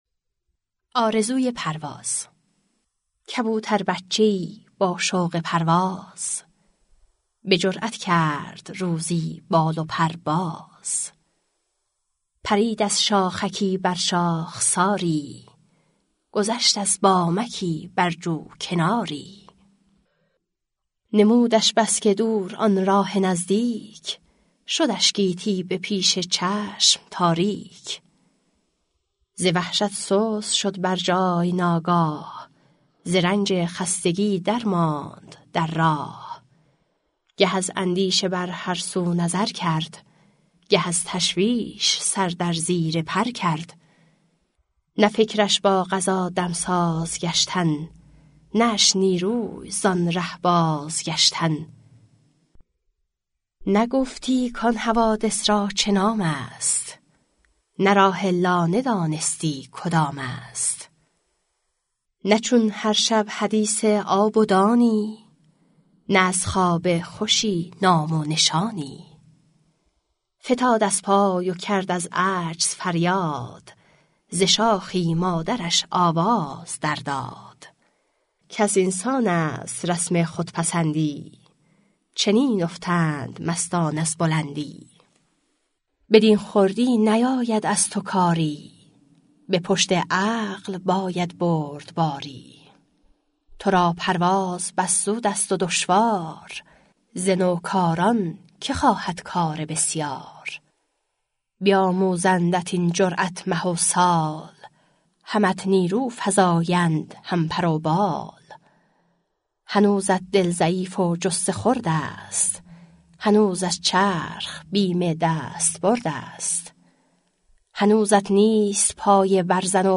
• دکلمه اشعار پروین اعتصامی «کبوتر بچه‌ای با شوق پرواز/ بجرئت کرد روزی بال و پر باز/ پرید از شاخکی بر شاخساری/ گذشت از بامکی بر جو کناری» (4:50)